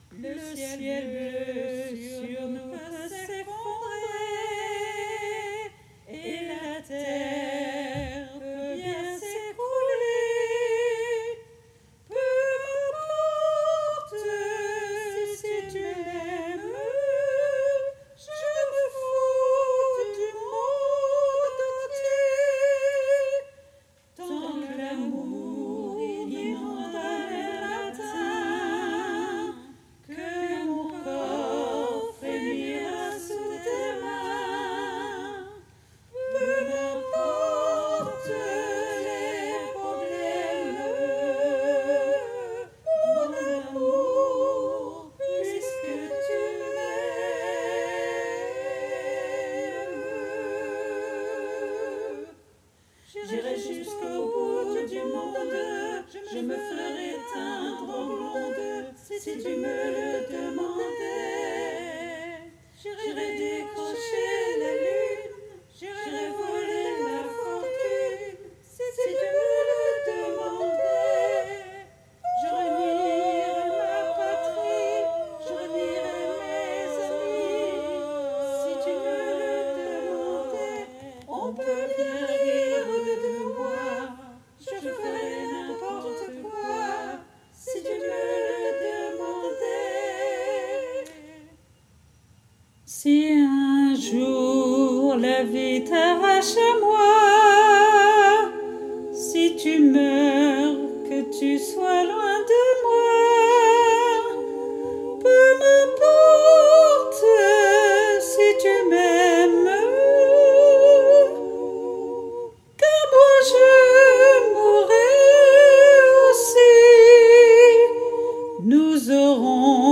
MP3 versions chantées
Solo